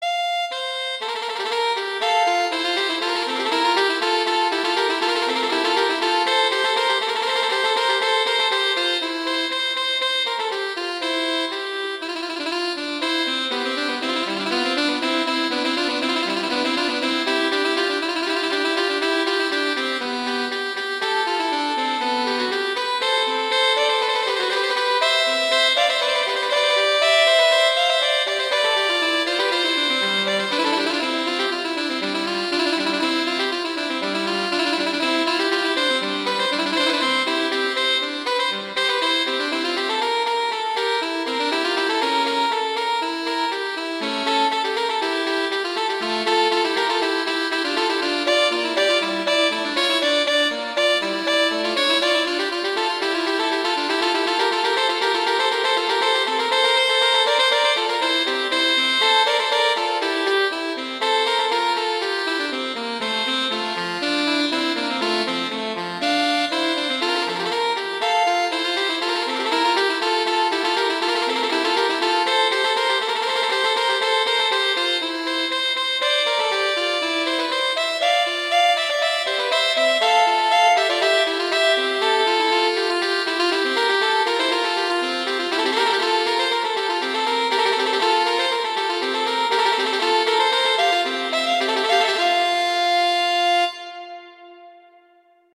transcription for two alto saxophones
classical